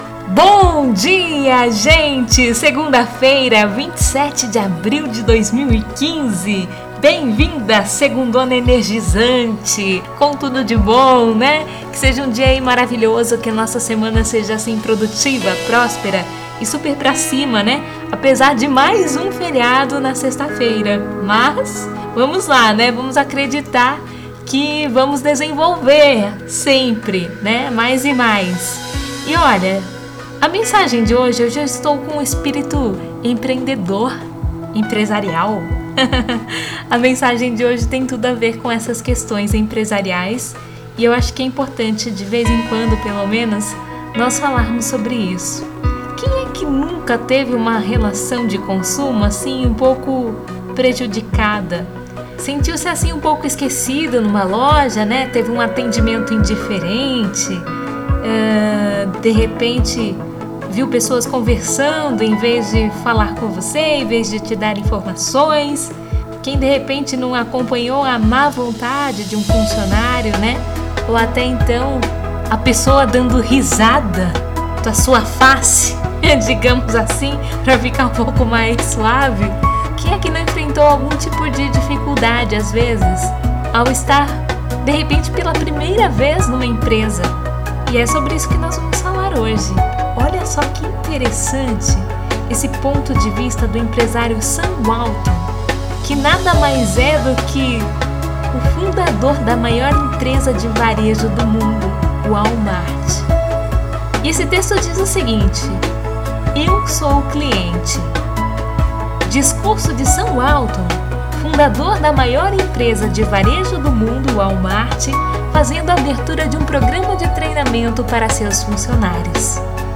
Discurso de Sam Walton, fundador da maior empresa de varejo do mundo, WALMART, fazendo a abertura de um programa de treinamento para seus funcionários: